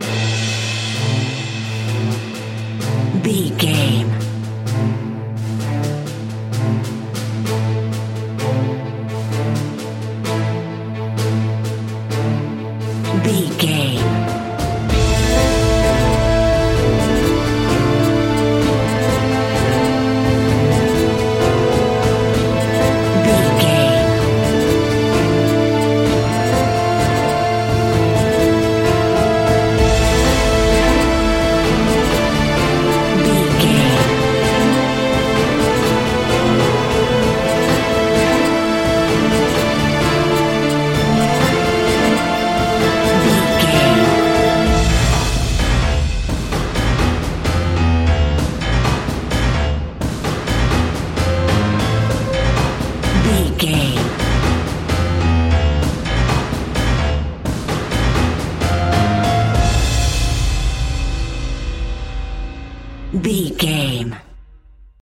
In-crescendo
Thriller
Aeolian/Minor
scary
ominous
dark
suspense
haunting
eerie
strings
synth
ambience
pads